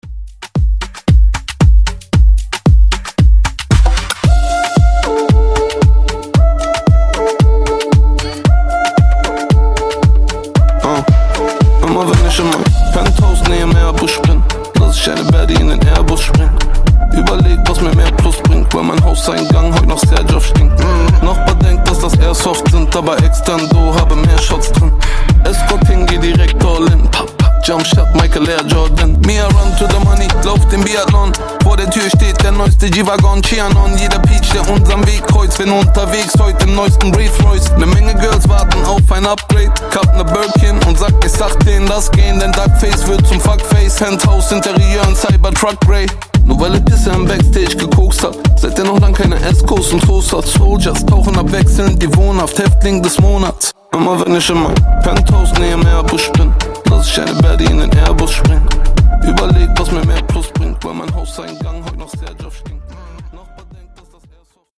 Genres: DANCE , EDM , TOP40
Clean BPM: 125 Time